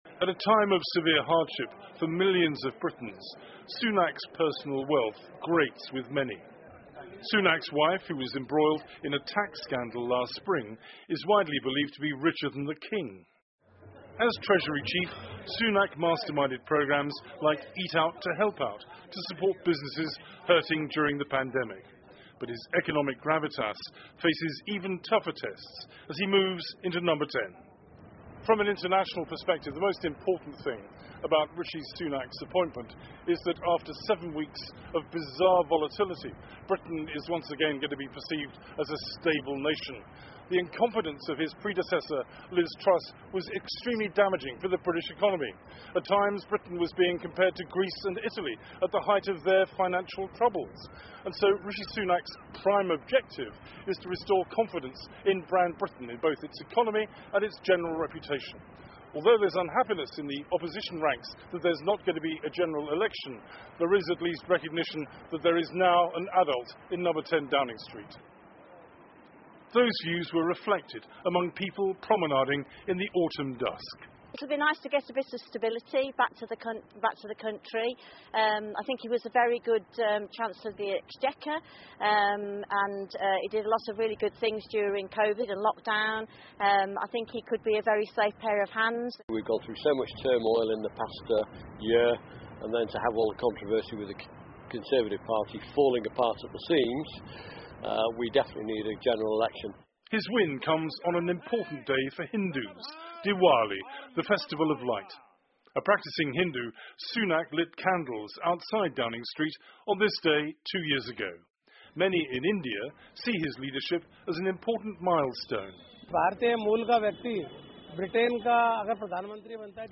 PBS高端访谈:英国首位印度裔首相--妻子比英国国王还富有（2） 听力文件下载—在线英语听力室